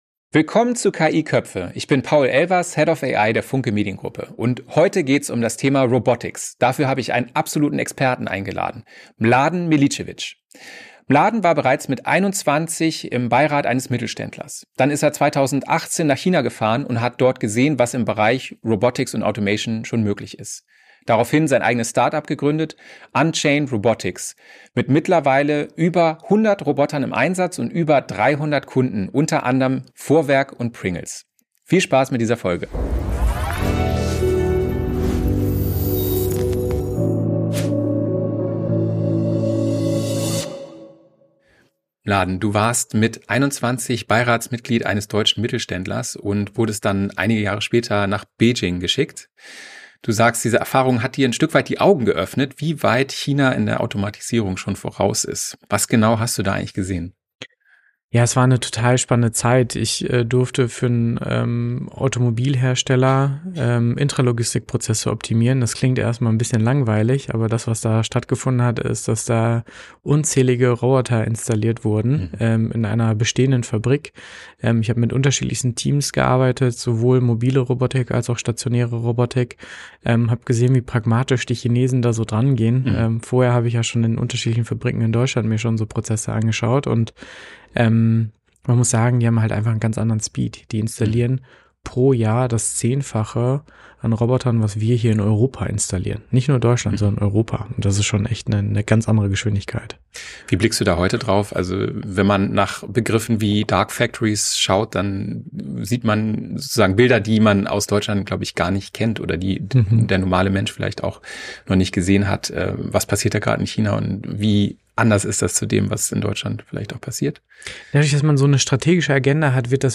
Ein Gespräch über den Unterschied zwischen Hype und Realität, Europas Automatisierungs-Chancen und warum humanoide Roboter näher sind, als viele denken.